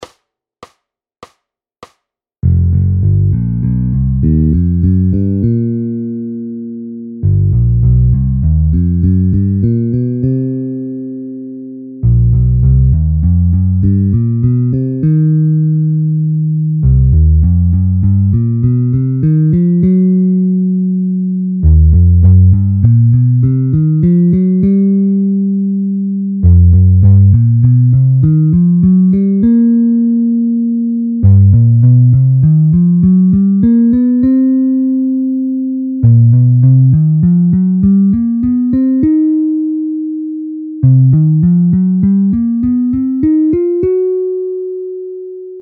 ZVUKOVÁ UKÁZKA  stupnice bluesová C
03-C bluesová.mp3